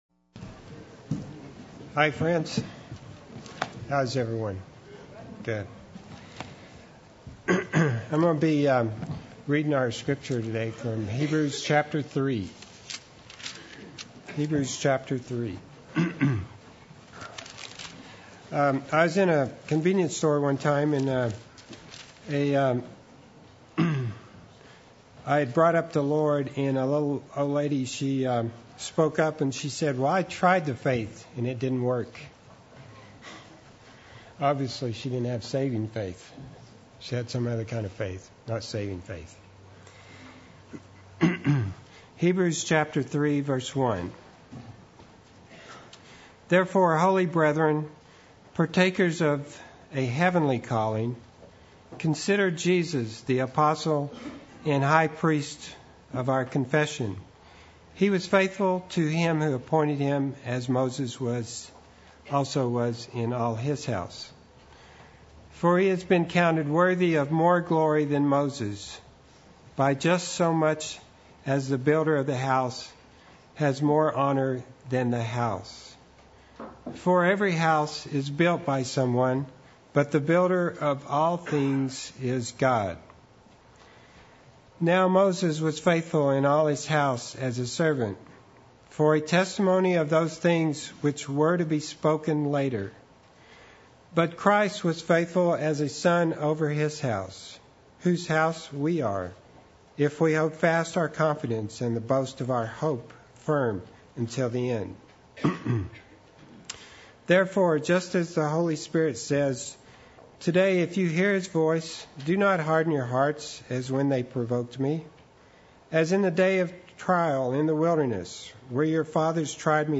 Play Sermon Get HCF Teaching Automatically.
Responding Properly to the Word Sunday Worship